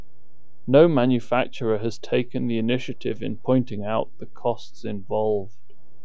HAS-Corpus / Audio_Dataset /sad_emotion /1507_SAD.wav
1507_SAD.wav